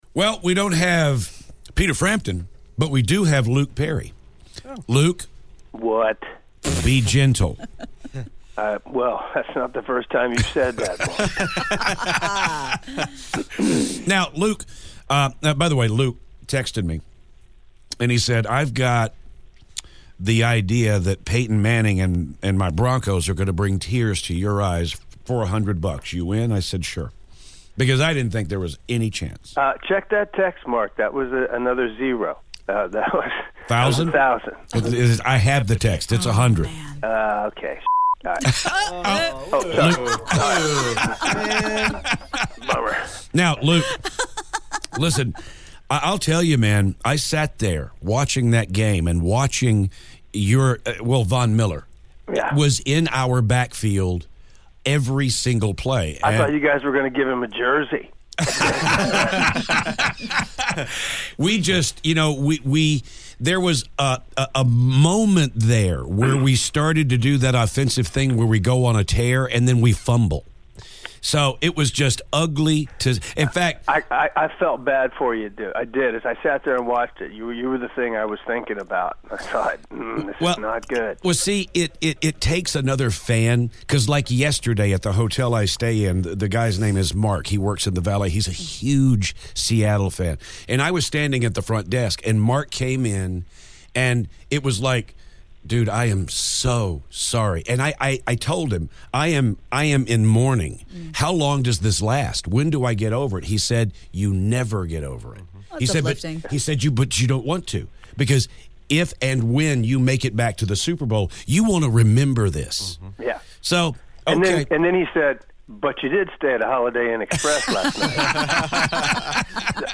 Luke Perry Phoner